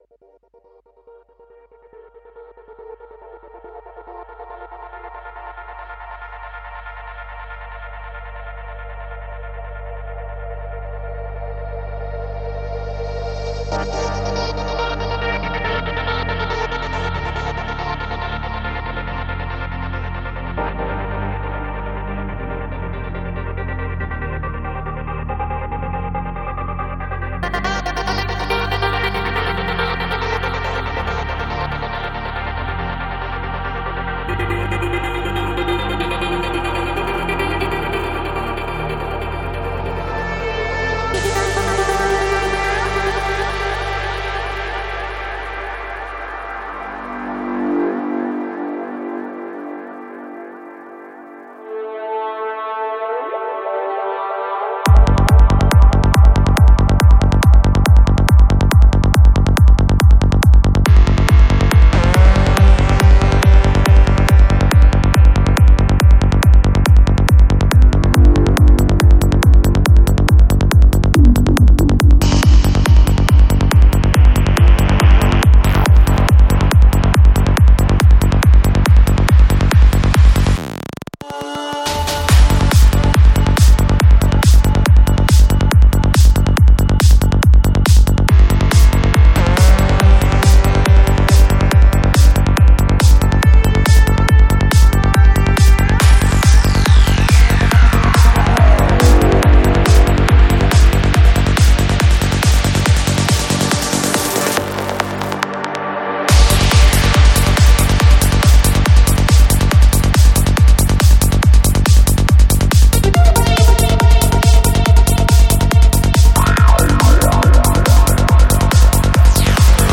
Жанр: Psy-Trance